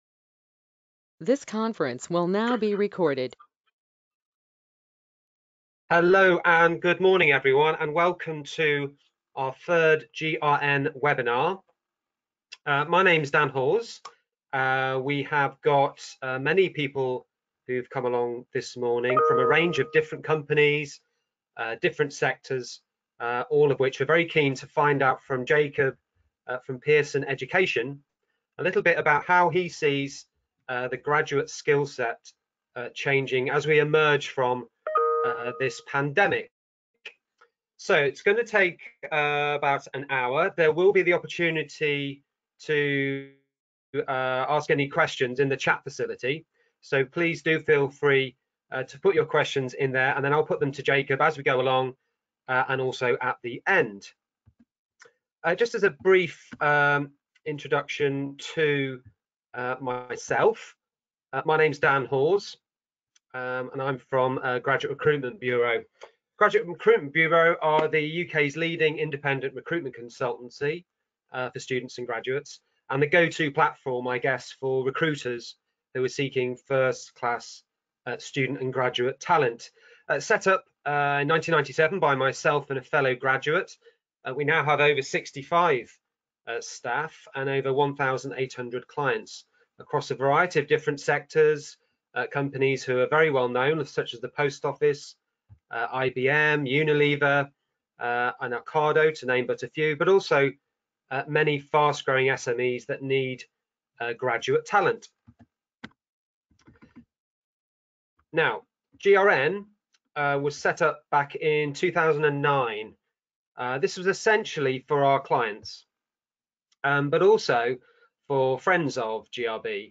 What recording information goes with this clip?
As we plan for the new normal post-Covid, what do we expect from graduates joining the workforce? Have the business needs, mission or projects changed requiring a rethink in the skill sets we demand. Join us for an engaging webinar with education experts Pearson, as we discuss...